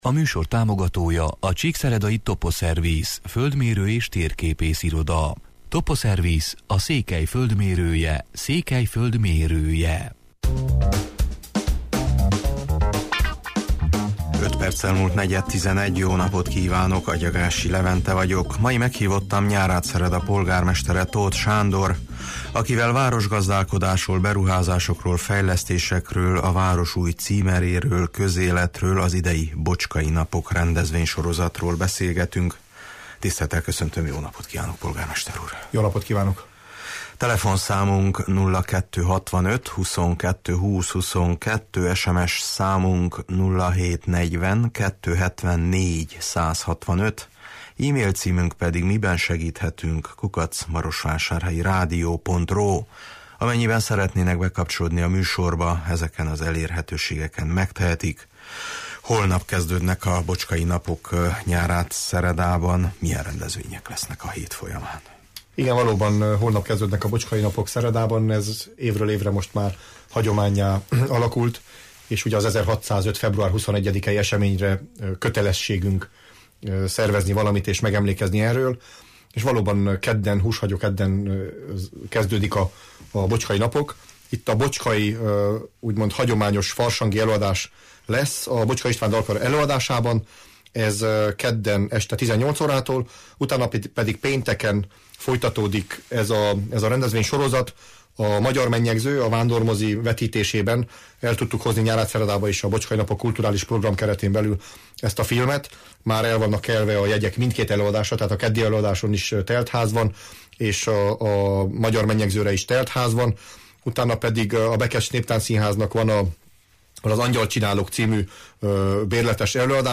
Mai meghívottam Nyárádszereda polgármestere, Tóth Sándor, akivel a rendezvénysorozatról, városgazdálkodásról, beruházásokról, fejlesztésekről, a város új címeréről, közéletről beszélgetünk: